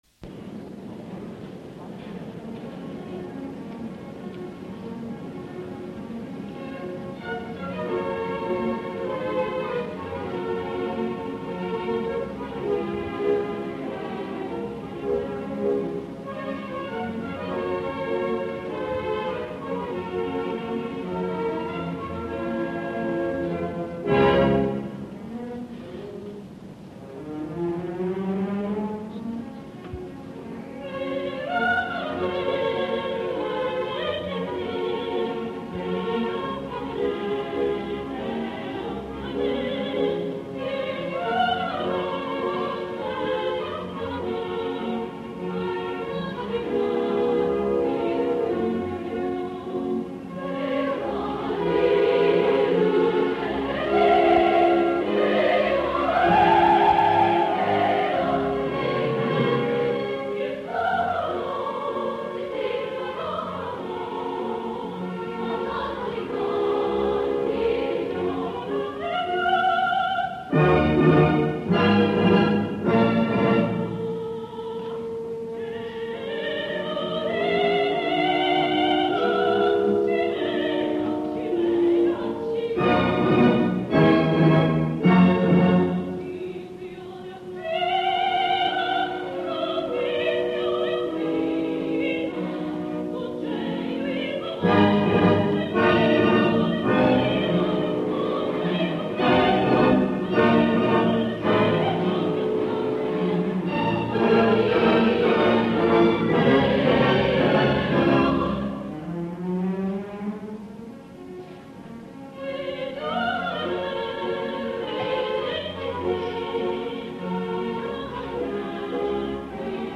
Ines – Lela Alice Terrell (Lella Cuberli)
Direttore – Nicola Rescigno
Dallas Opera, 7 novembre 1971